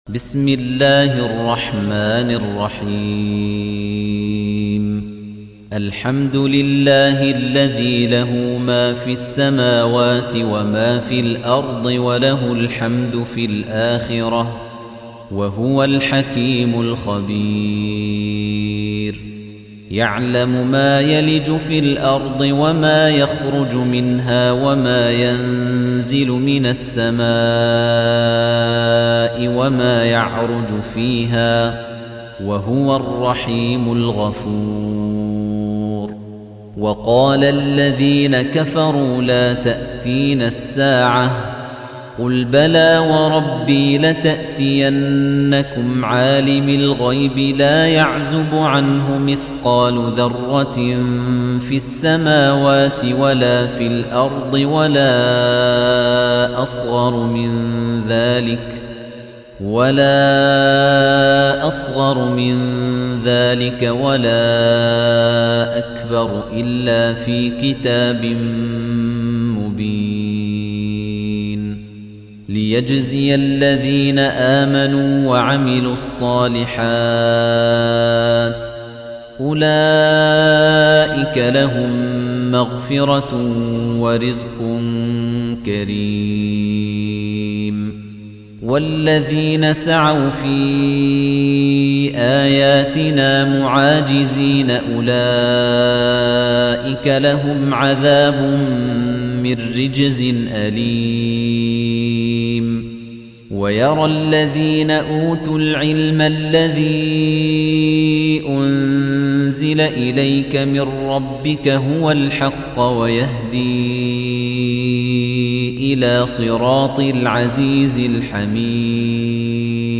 تحميل : 34. سورة سبأ / القارئ خليفة الطنيجي / القرآن الكريم / موقع يا حسين